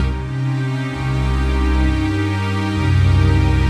Index of /musicradar/80s-heat-samples/130bpm
AM_80sOrch_130-C.wav